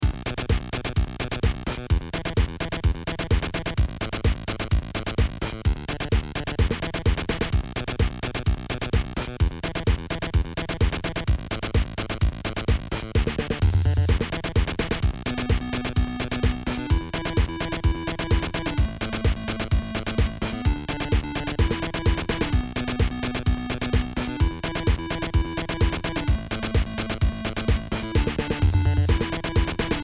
programmatically generated 8-bit musical loops